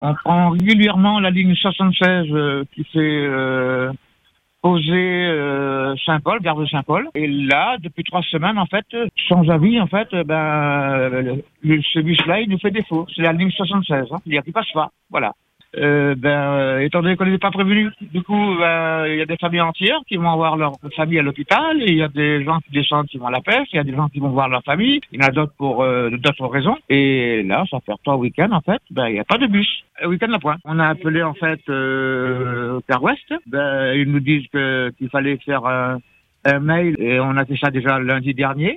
Selon cet auditeur saint-paulois, plus aucun bus ne circule les samedis et dimanches depuis trois semaines.